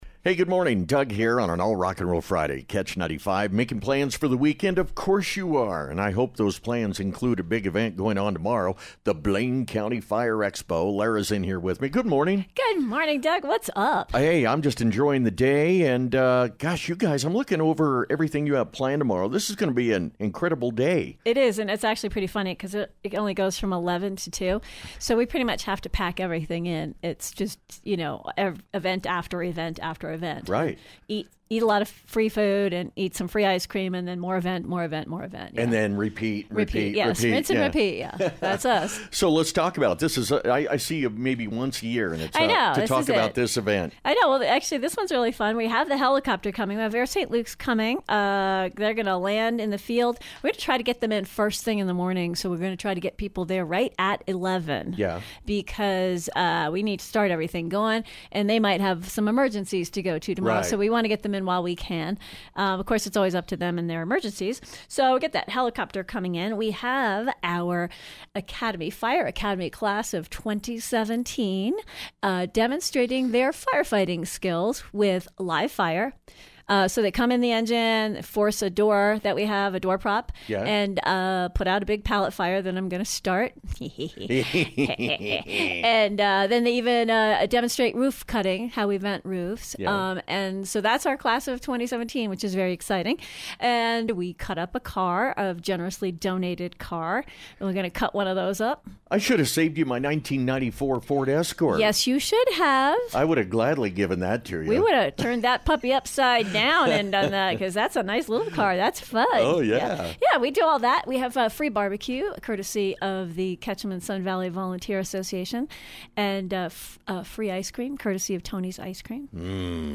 Blaine County Fire Expo Interview